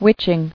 [witch·ing]